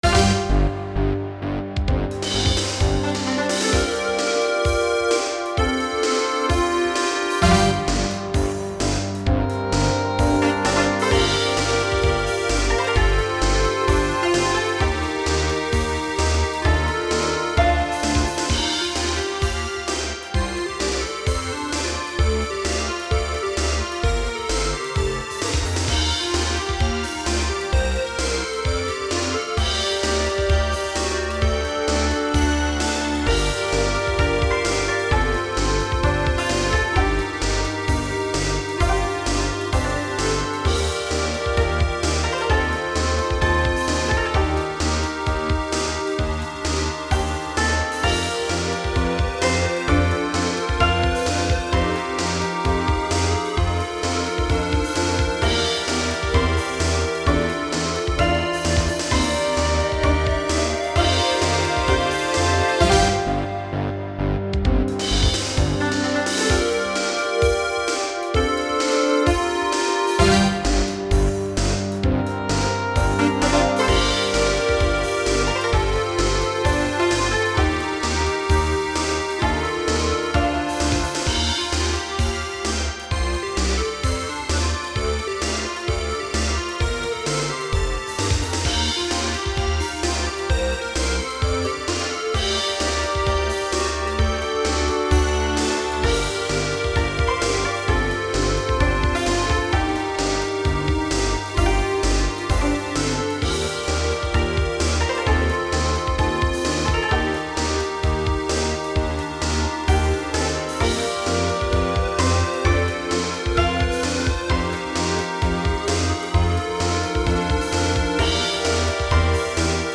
This is a short rpg-type song I made in 2015 (kinda).A friend of mine asked me to do some tracks for one of his Rpg Maker projects.
So my point is it could be a great track for JRPG-project or shonen-inspired ambience.